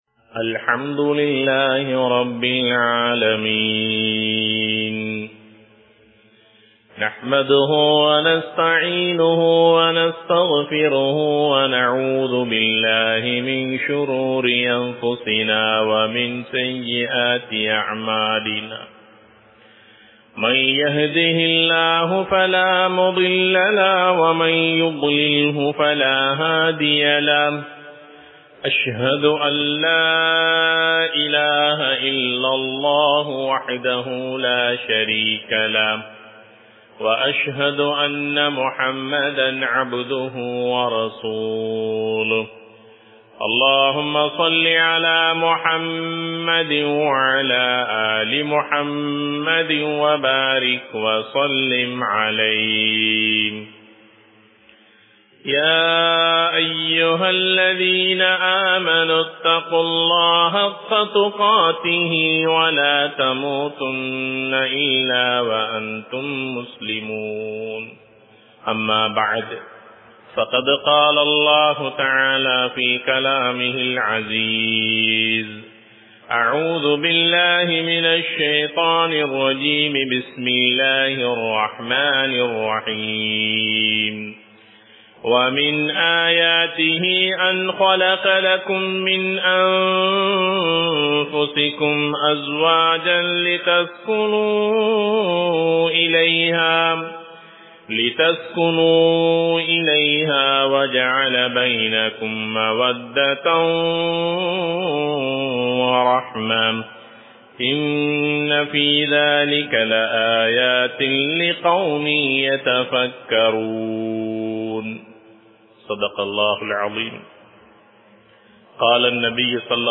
இஸ்லாம் கூறும் குடும்பம் | Audio Bayans | All Ceylon Muslim Youth Community | Addalaichenai
Muhiyaddeen Grand Jumua Masjith